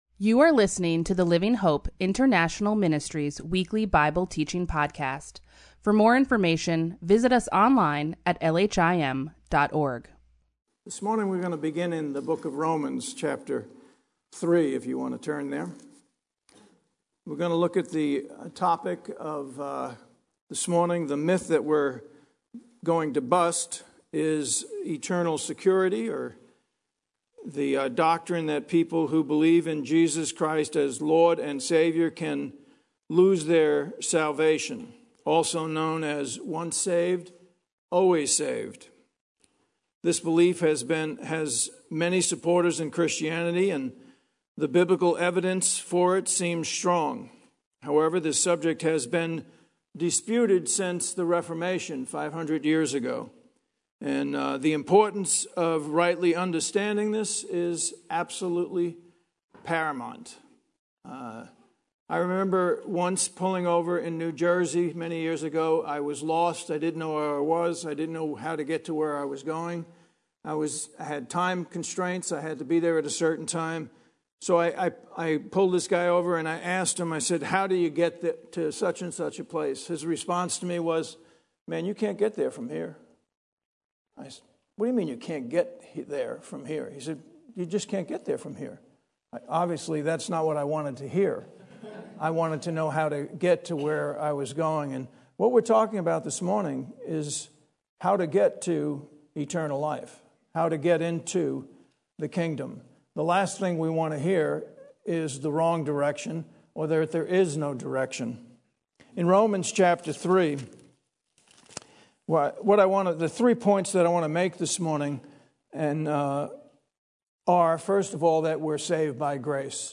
LHIM Weekly Bible Teaching